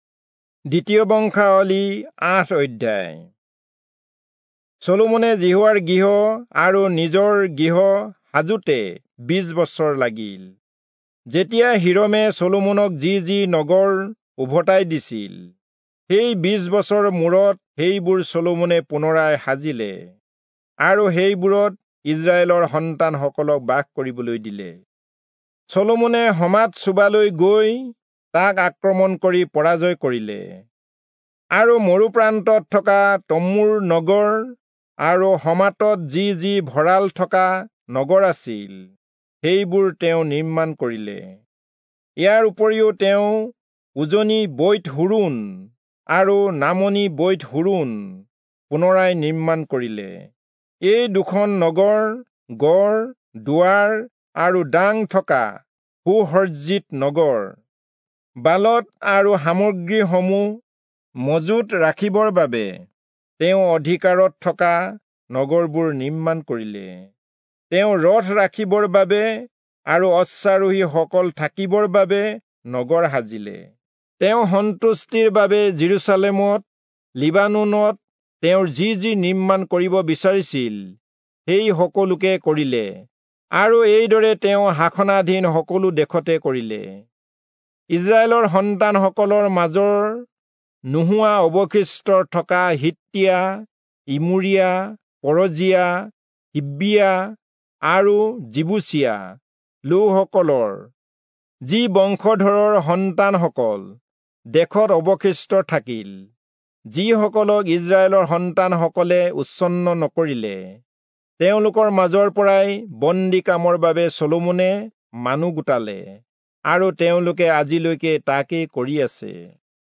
Assamese Audio Bible - 2-Chronicles 11 in Litv bible version